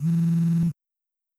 vib.wav